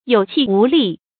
有氣無力 注音： ㄧㄡˇ ㄑㄧˋ ㄨˊ ㄌㄧˋ 讀音讀法： 意思解釋： 氣：氣息；聲音；力：力氣；精神。